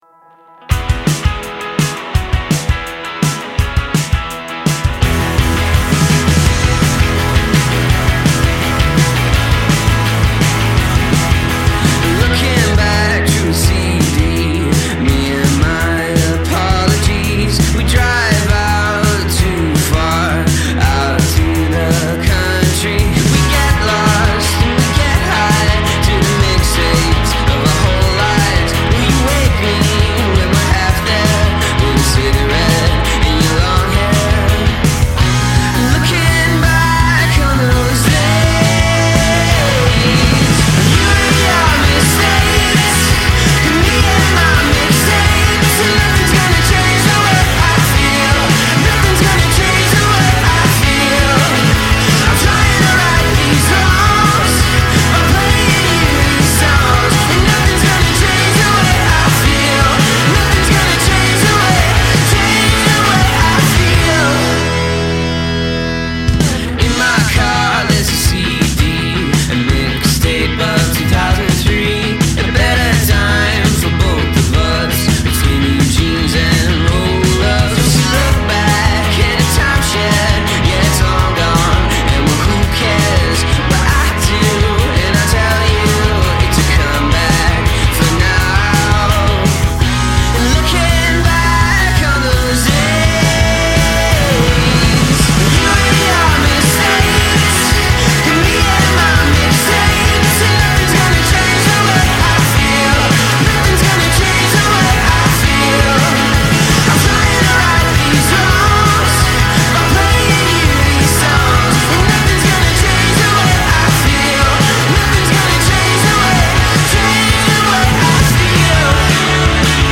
indie-rockers
a scintillating burst of twenty-first century rock n’ roll